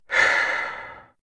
sigh3.ogg